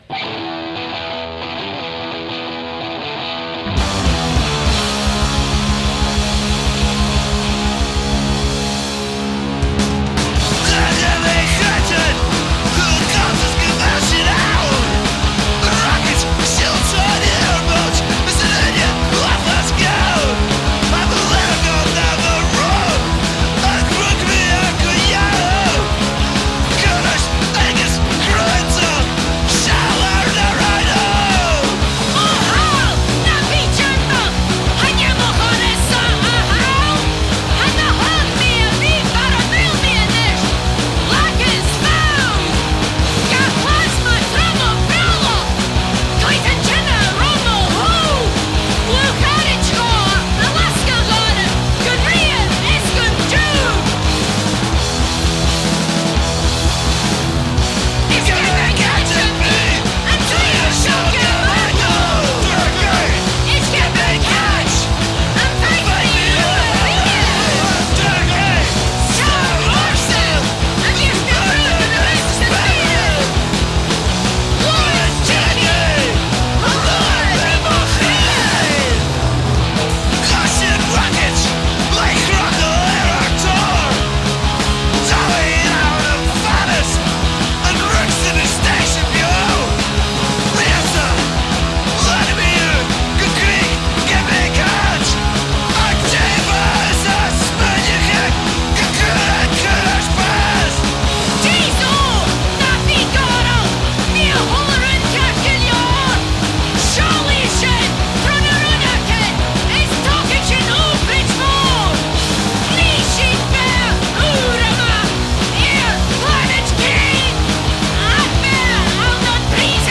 uile deiseil agus a’ ròcadh gu cruaidh!